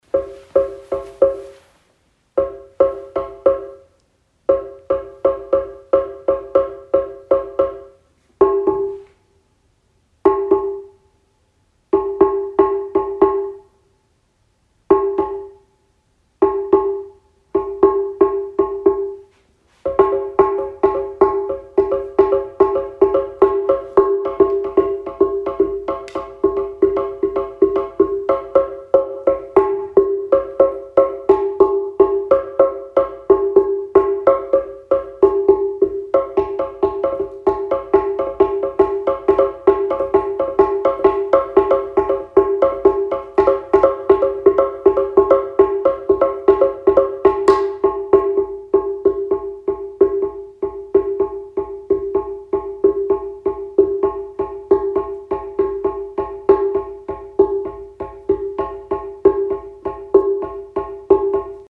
Schlitztrommel
Slit Drum
Played musical instrument: Slit Drum, Cameroon